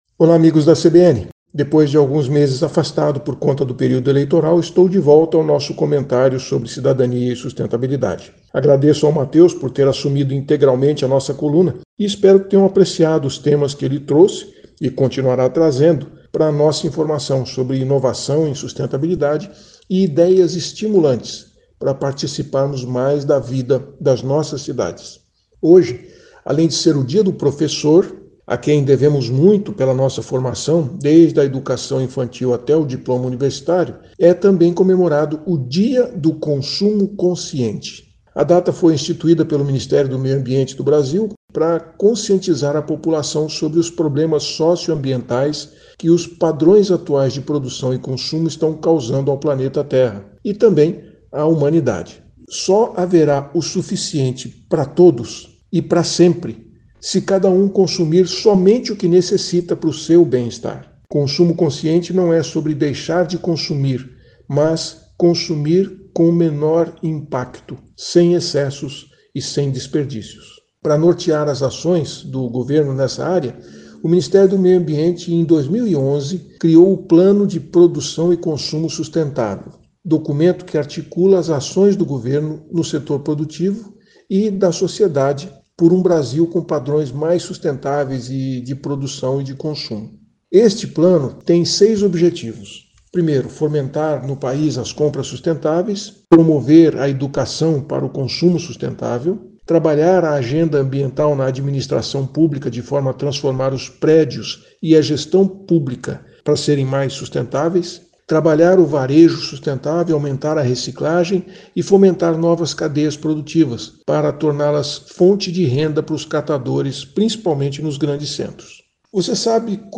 CBN Cidadania e Sustentabilidade, com Silvio Barros, fala sobre atitudes sustentáveis feitos por instituições e pessoas.